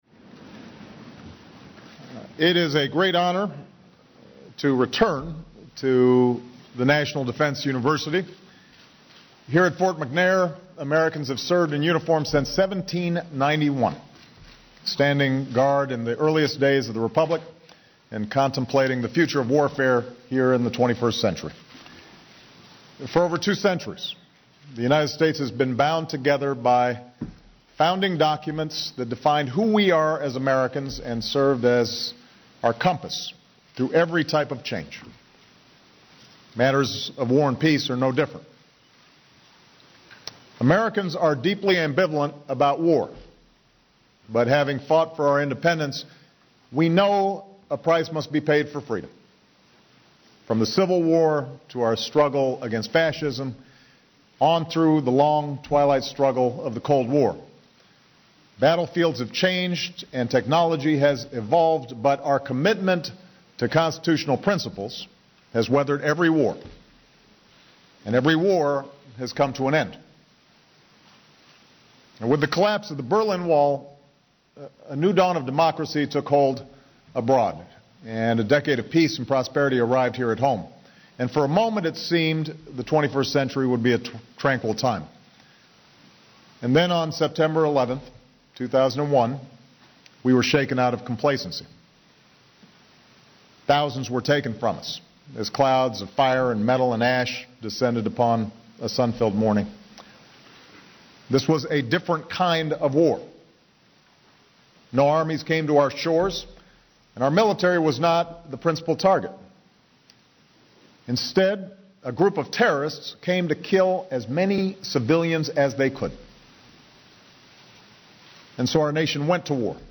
U.S. President Barack Obama delivers a major policy speech about a range of U.S. counter-terrorism issues